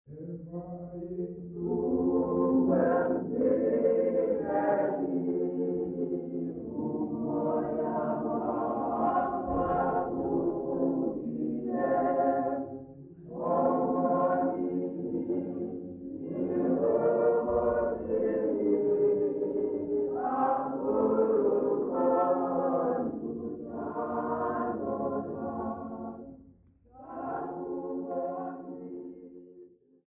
St Joseph's congregation
Sacred music South Africa
Africa South Africa Grahamstown, Eastern Cape sa
field recordings
Unaccompanied Catholic mass hymn